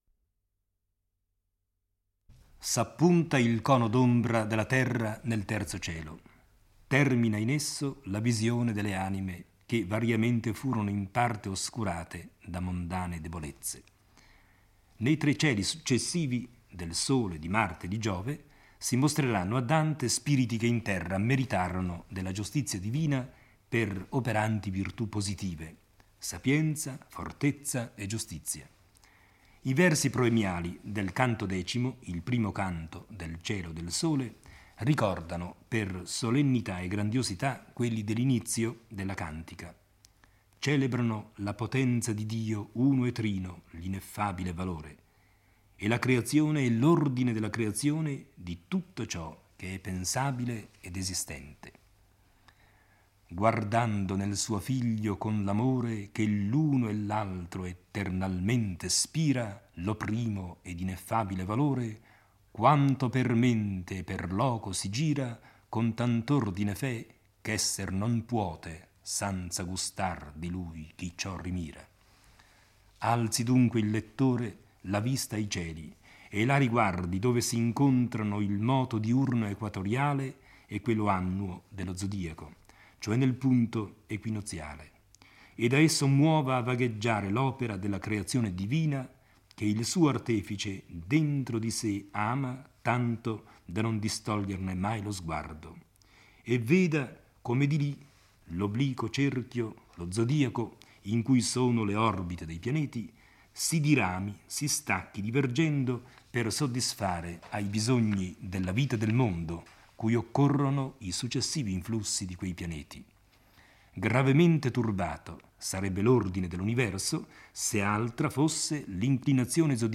legge e commenta il X canto del Paradiso. È il primo dei cinque canti dedicati al cielo del Sole.